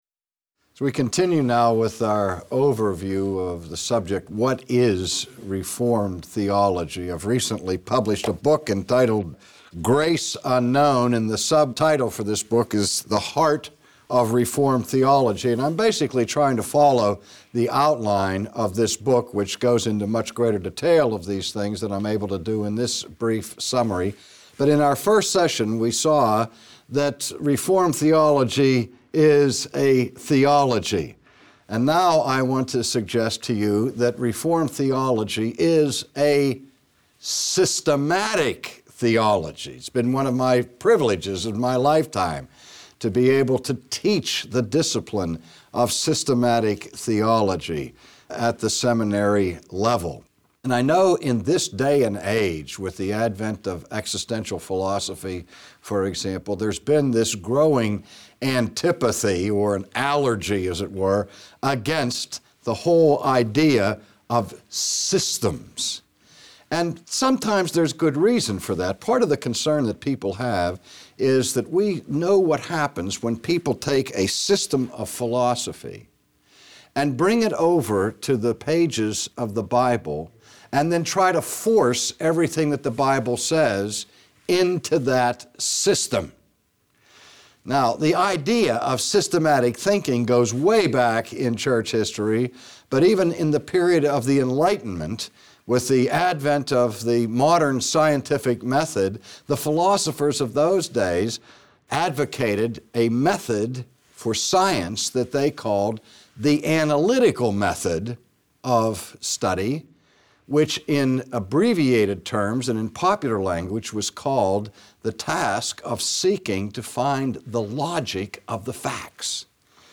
Lecture #2 : Catholic, Evangelical, and Reformed In his second lecture Dr. R.C Sproul moves on to show how Reformed theology is not just...